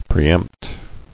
[pre·empt]